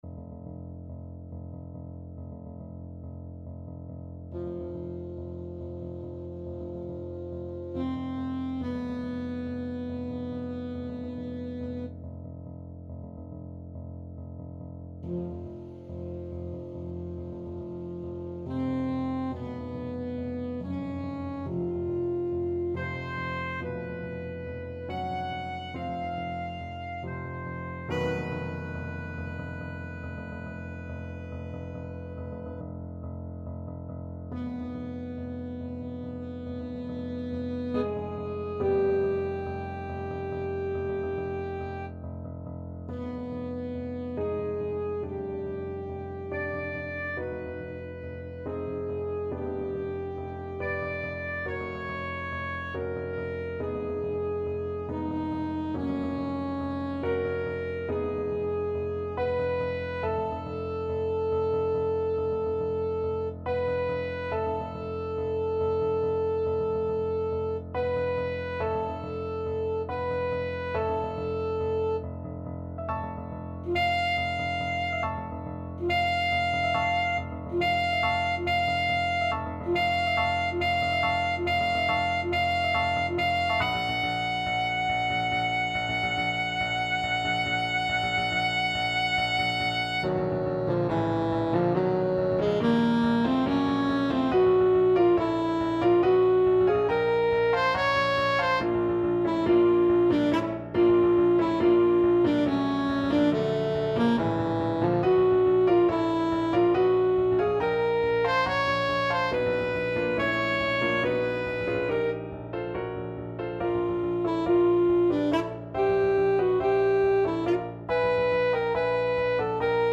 Alto Saxophone
5/4 (View more 5/4 Music)
Allegro = 140 (View more music marked Allegro)
Classical (View more Classical Saxophone Music)